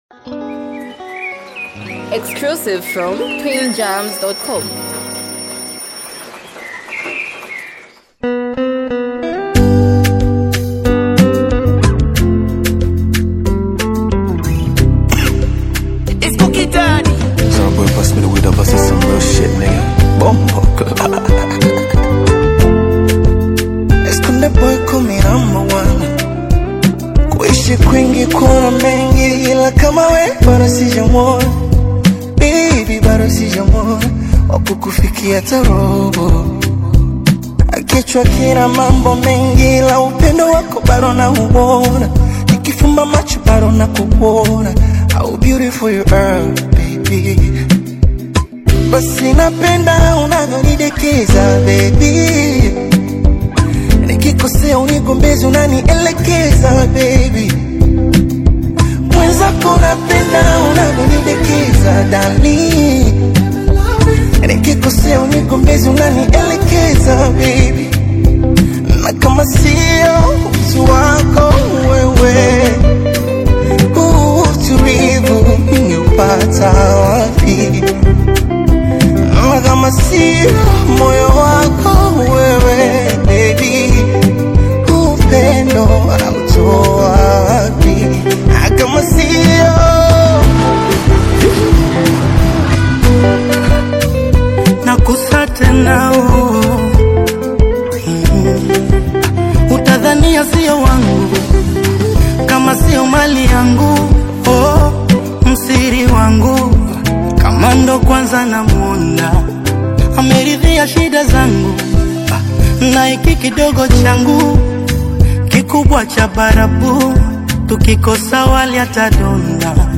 is a smooth blend of Bongo Flava and emotional storytelling